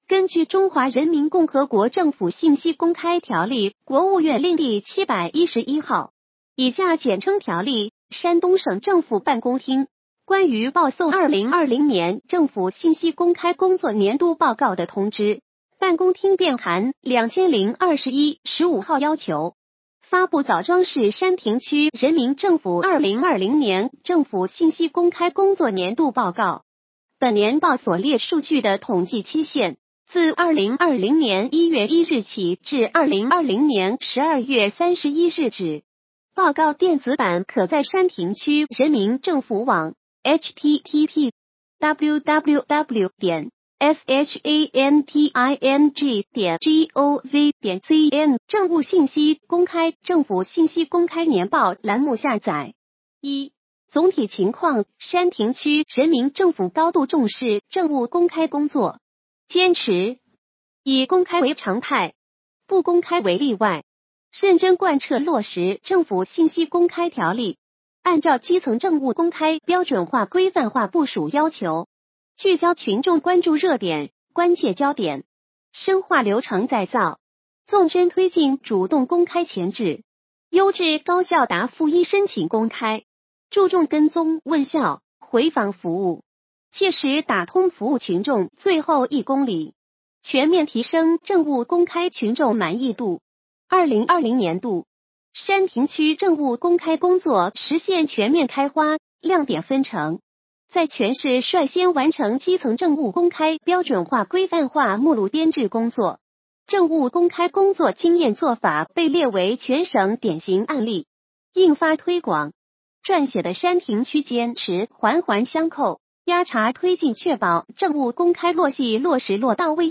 点击接收年报语音朗读 2020年山亭区人民政府信息公开工作年报 作者： 来自： 时间：2021-02-20 根据《中华人民共和国政府信息公开条例》 ( 国务院令第 711 号，以下简称《条例》 ) 、山东省政府办公厅《关于报送 2020 年政府信息公开工作年度报告的通知》（办公厅便函〔 2021 〕 15 号）要求，发布枣庄市山亭区人民政府 2020 年政府信息公开工作年度报告。